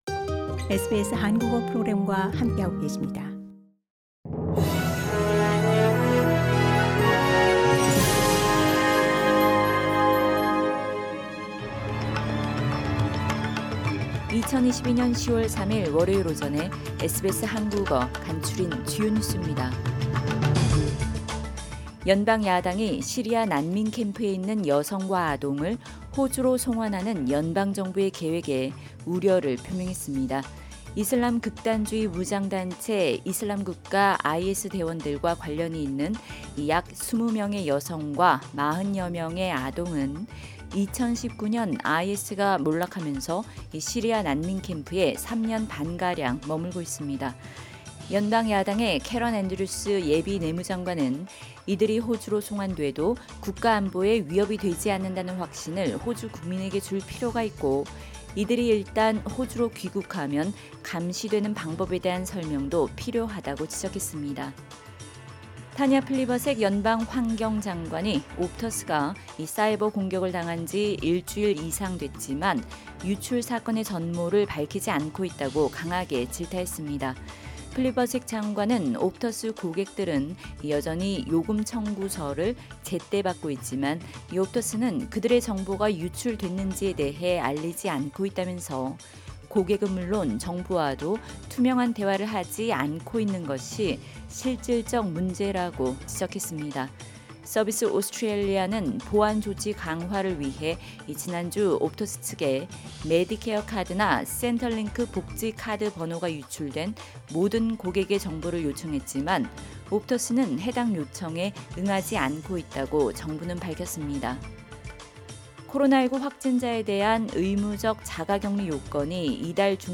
SBS 한국어 아침 뉴스: 2022년 10월 3일 월요일